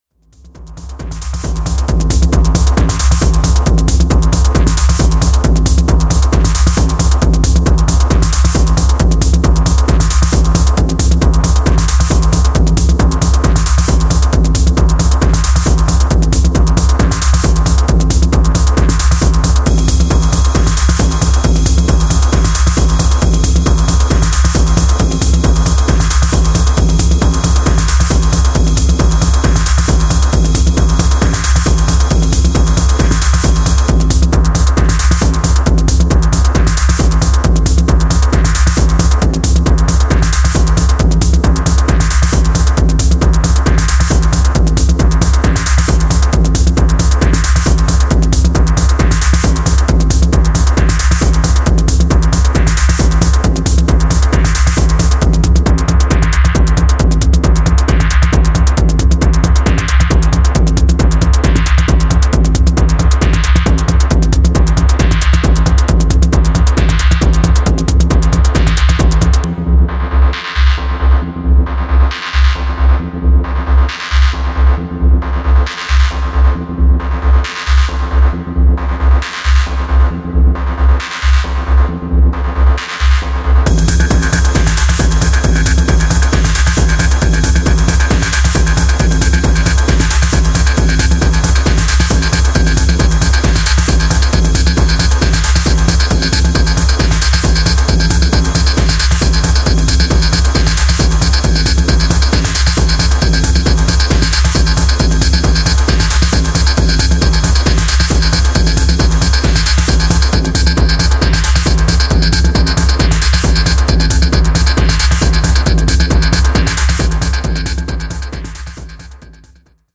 FUNCTIONAL TECHNO CRAFTED TO ELEVATE